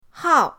hao4.mp3